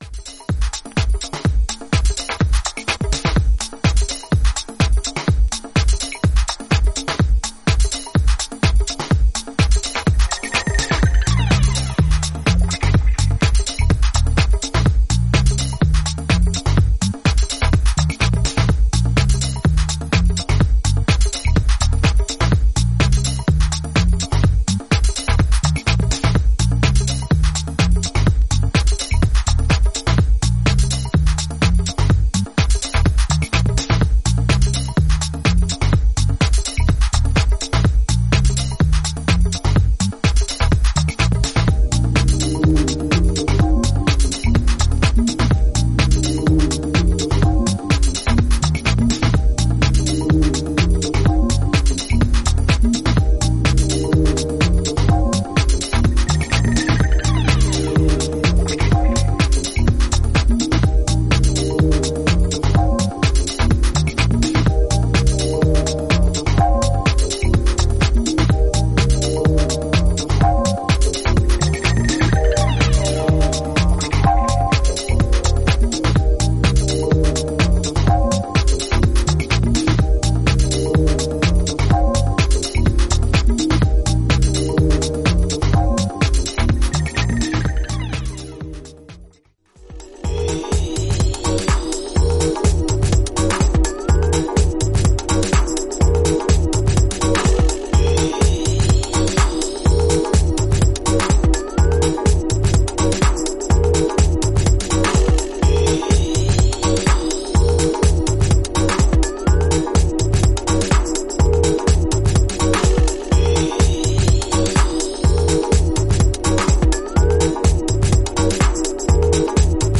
パーカッシブなビートに黒いベースライン、フィルターで抑揚をつけたシンセが相まり展開する